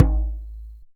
DJEM.HIT08.wav